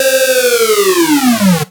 VEC3 FX Alarm 04.wav